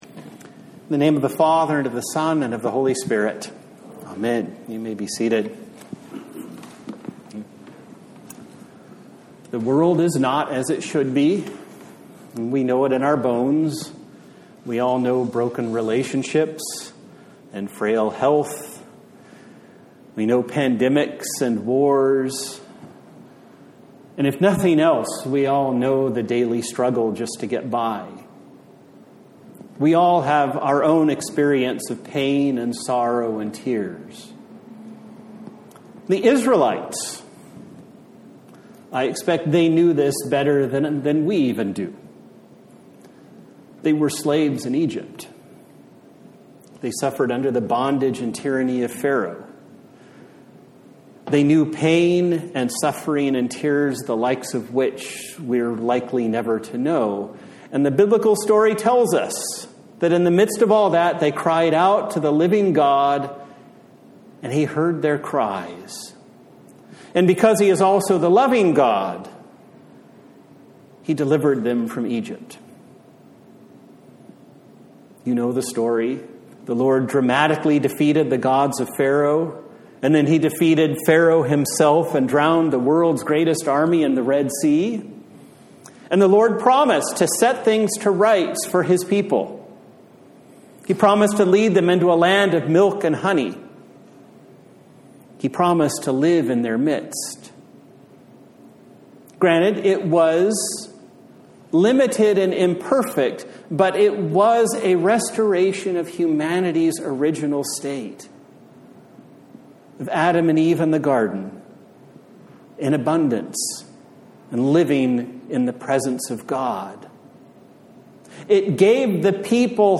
A Sermon for the Seventeenth Sunday after Trinity